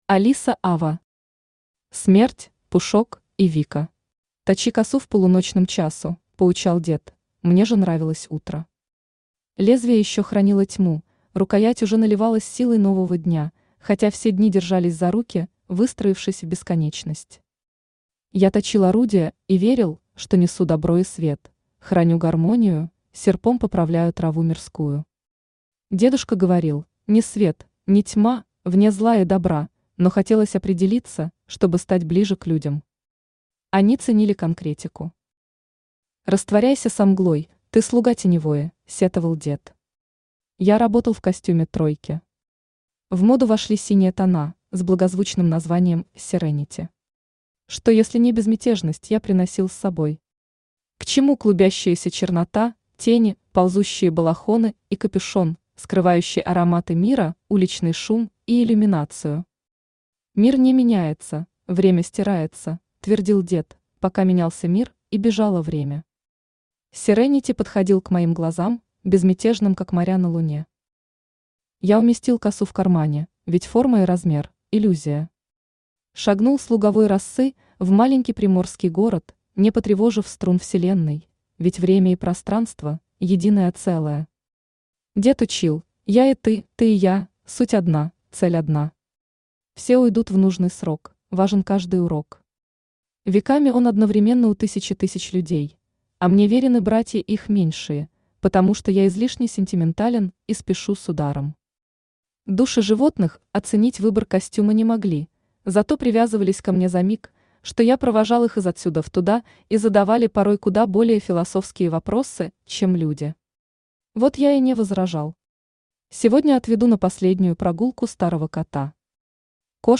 Аудиокнига Смерть, Пушок и Вика | Библиотека аудиокниг
Aудиокнига Смерть, Пушок и Вика Автор Алиса Аве Читает аудиокнигу Авточтец ЛитРес.